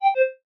12. notification6